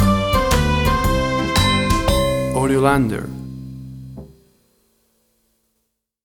Upbeat, uptempo and exciting!
Tempo (BPM): 115